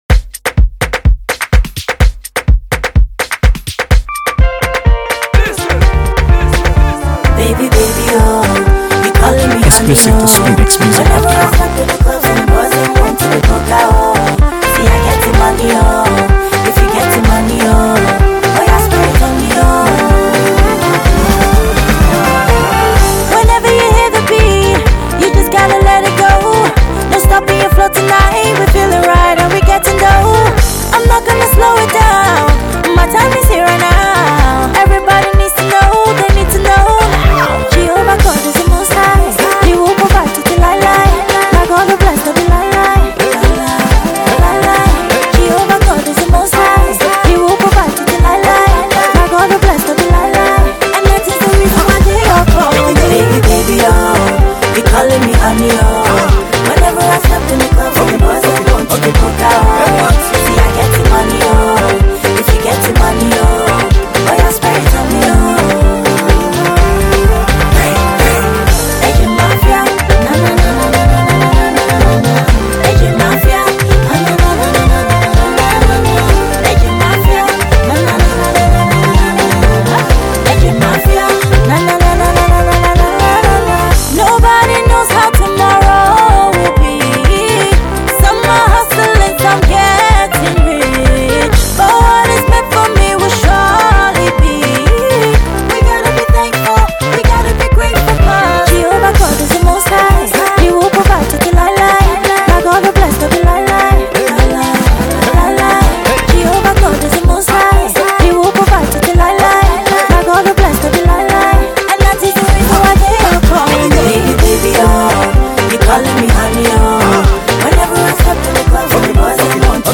AfroBeats | AfroBeats songs
This mid-tempo jam
combines infectious vibes with danceable instrumentals
It’s a groovy tune